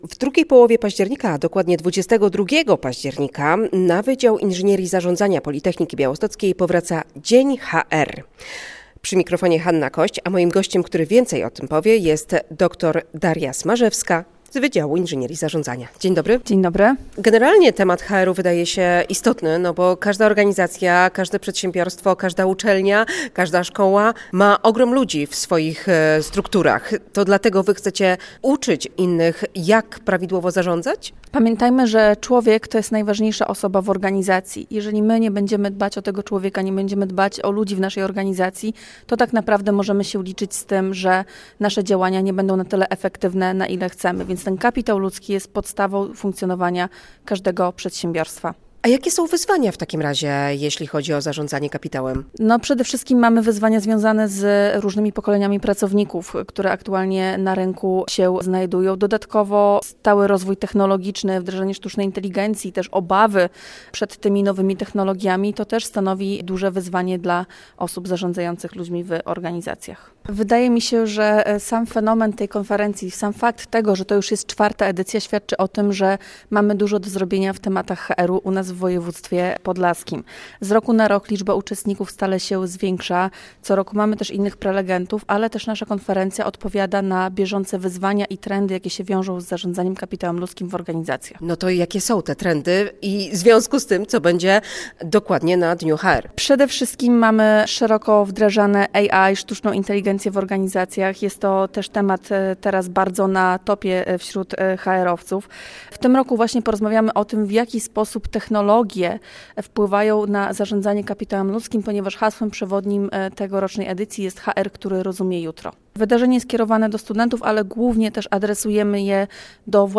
Zapowiedź